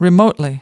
13. remotely (adv) /rɪˈməʊtli/: từ xa